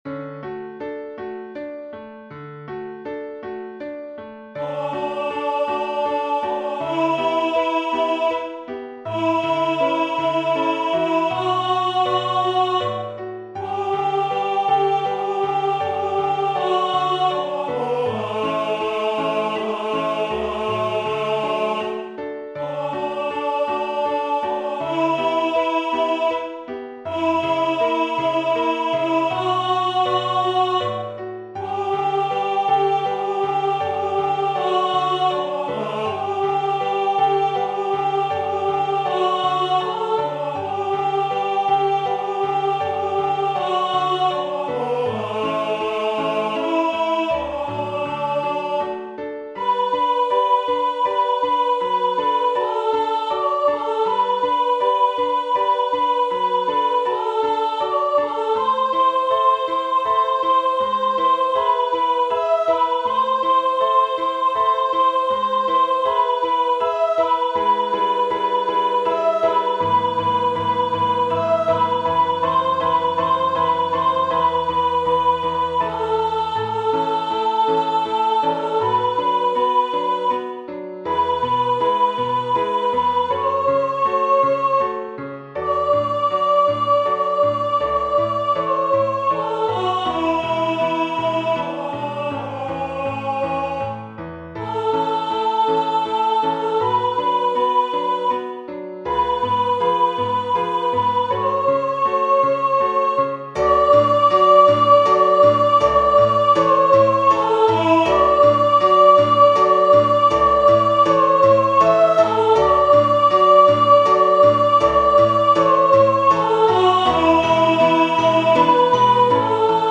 sopraan